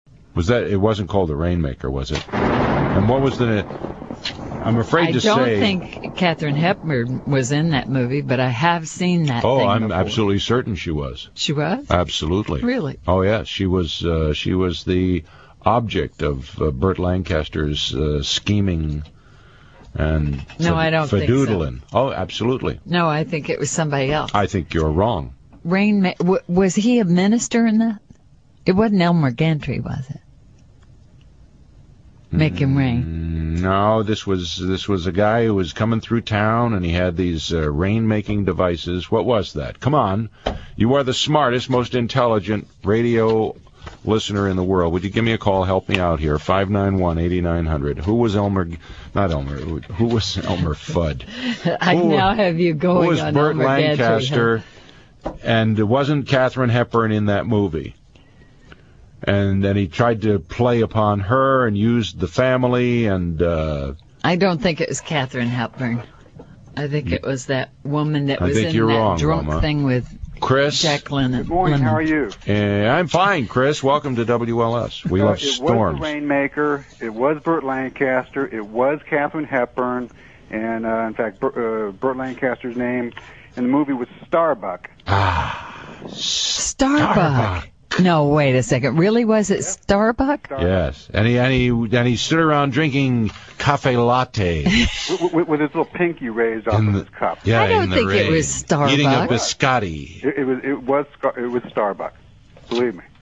United States, Chicago